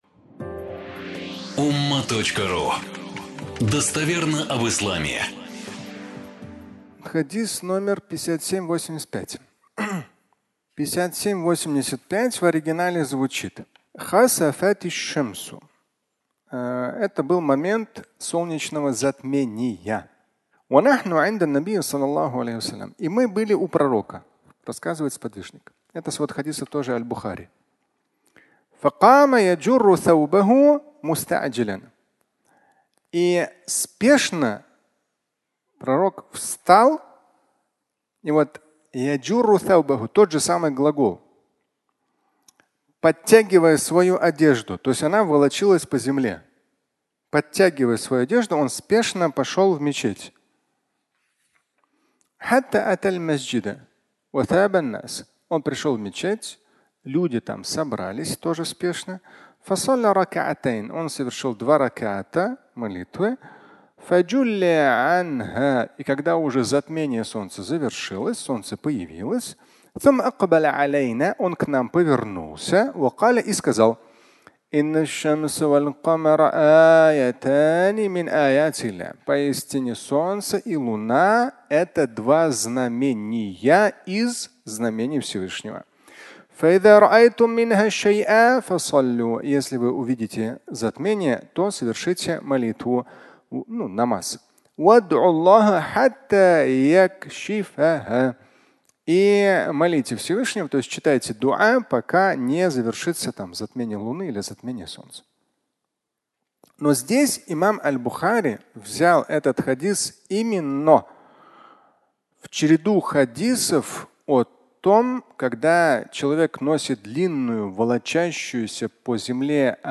Пророк и одежда (аудиолекция)